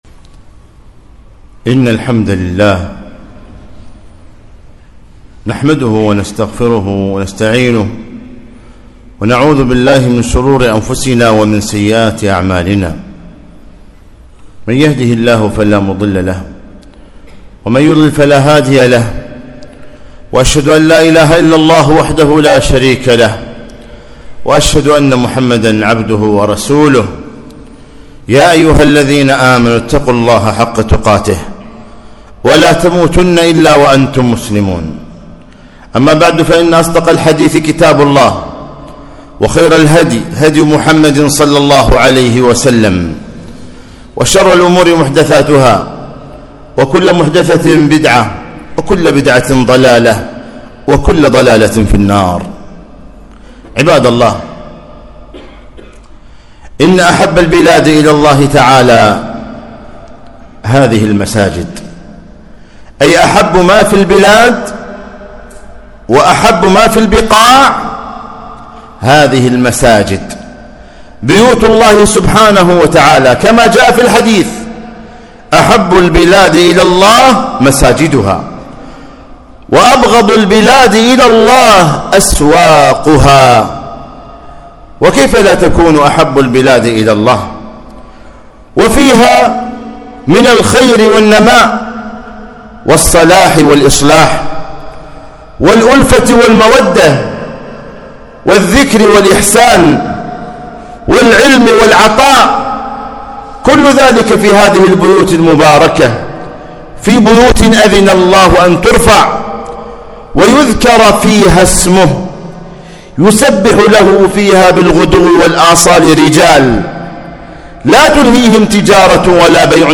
خطبة - أحب البلاد إلى الله مساجدها